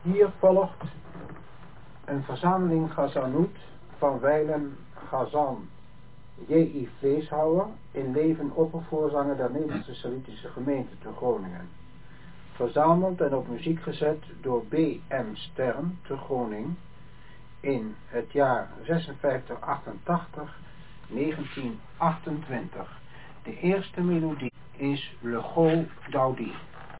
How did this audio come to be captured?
I used a cassette for the main part because the quality is slightly better. Further, the cassette has an introduction and some explanation, which were partly converted to text on the CD cover and the enclosed booklet.